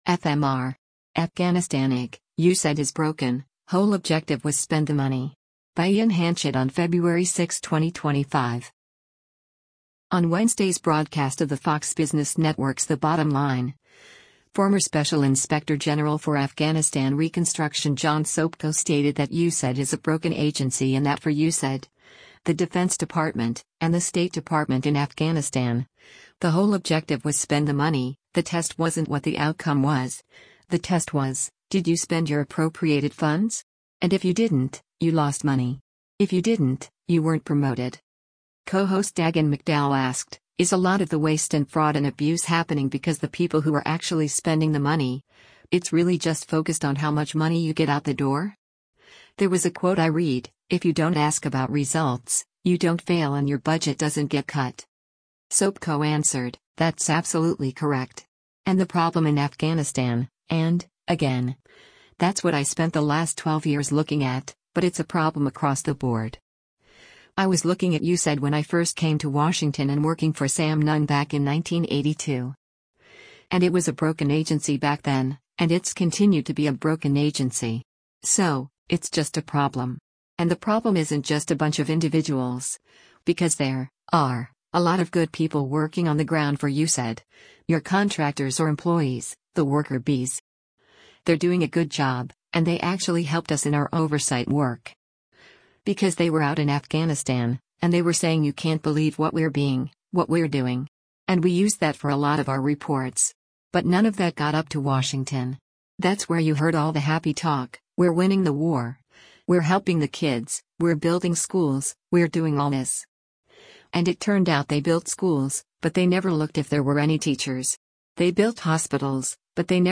Co-host Dagen McDowell asked, “Is a lot of the waste and fraud and abuse happening because the people who are actually spending the money, it’s really just focused on how much money you get out the door? There was a quote I read, if you don’t ask about results, you don’t fail and your budget doesn’t get cut.”